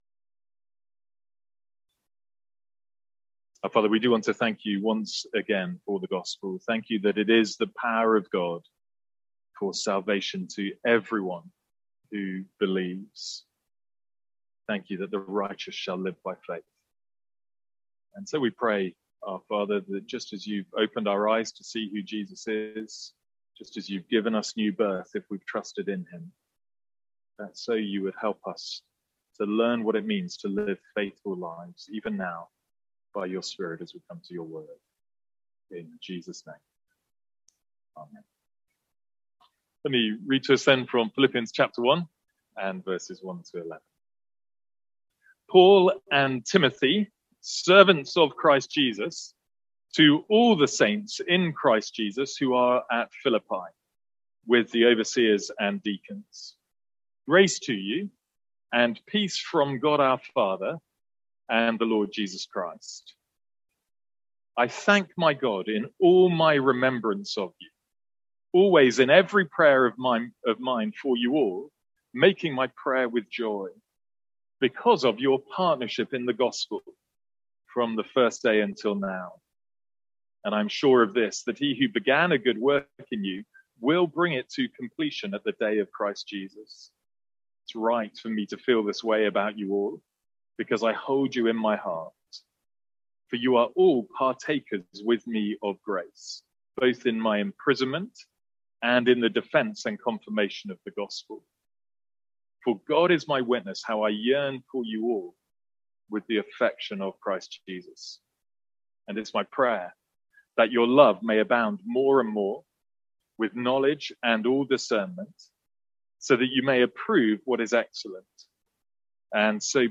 Sermons | St Andrews Free Church
From our morning series in Philippians.